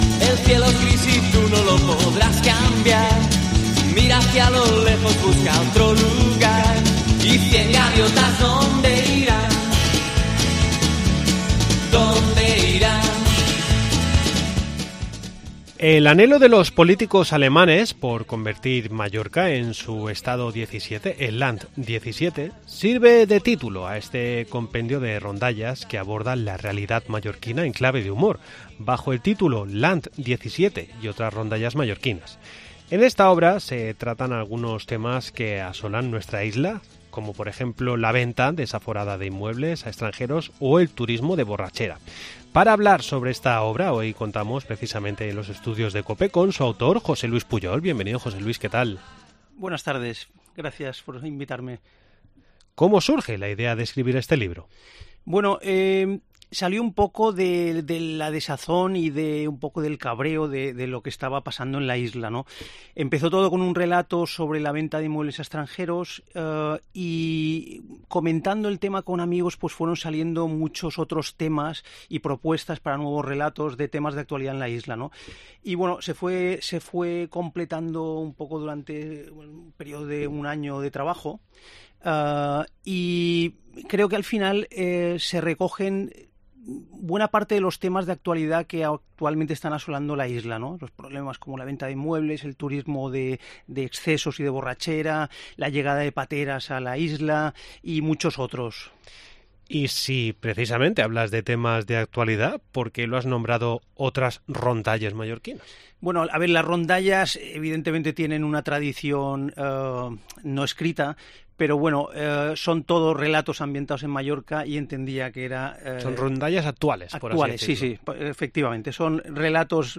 Visita nuestros estudios para dialogar sobre su novela